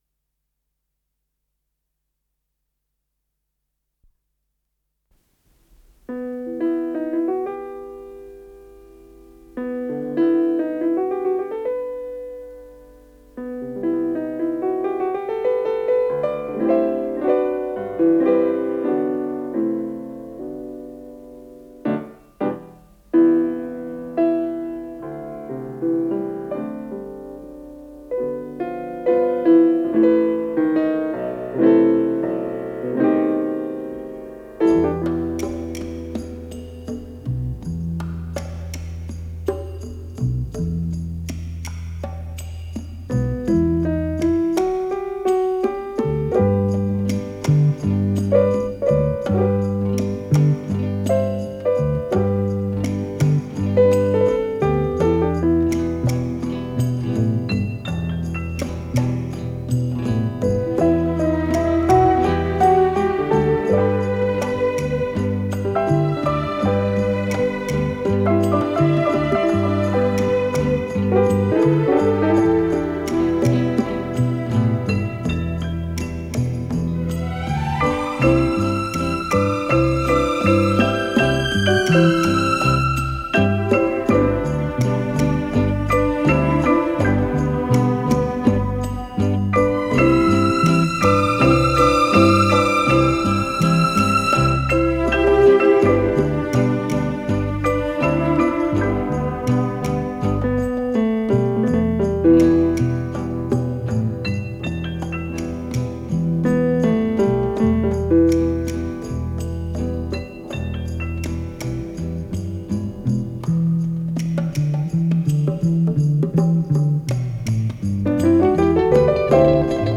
с профессиональной магнитной ленты
переложение для эстрадного оркестра